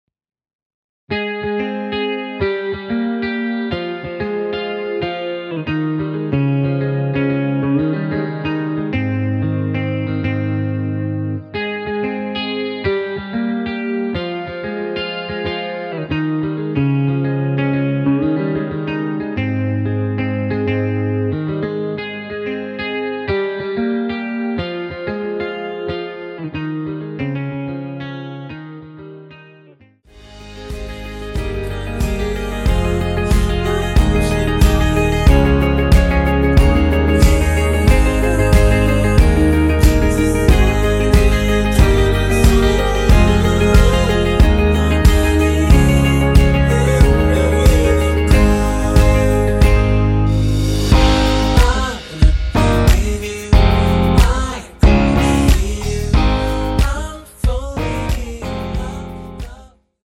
원키에서(-1)내린 (2절 삭제)하고 진행 되는 코러스 포함된 MR입니다.
Ab
앞부분30초, 뒷부분30초씩 편집해서 올려 드리고 있습니다.